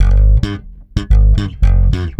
-AL DISCO.A.wav